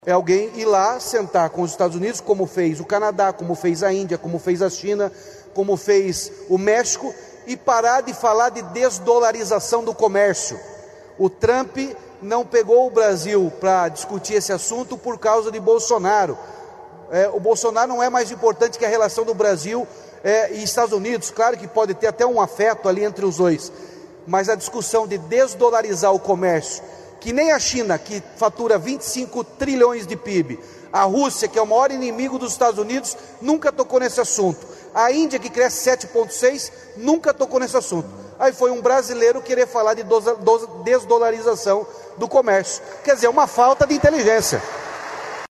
O governador do Paraná, Ratinho Junior (PSD), participou, neste sábado (26), de um painel durante o Expert XP, evento promovido pela XP Investimentos em São Paulo.